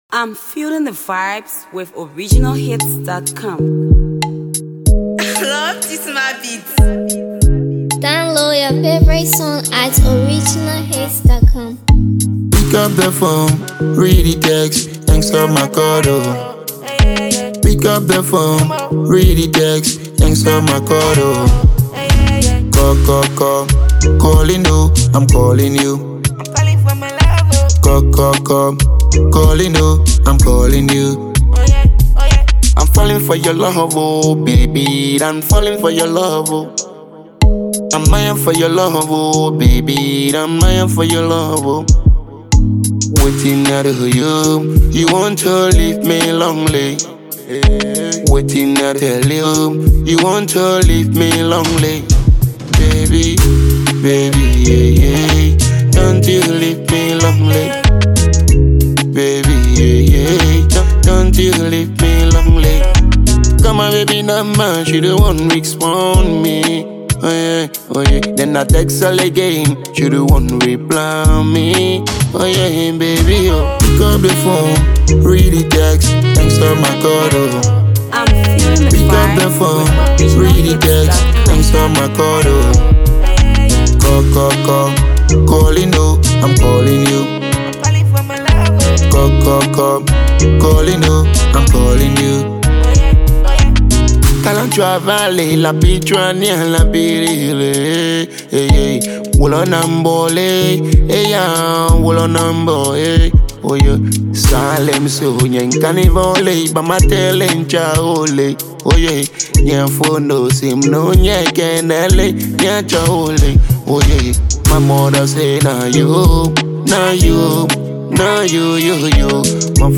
Upcoming Afropop sensation
is a pure vibe from the young crooner.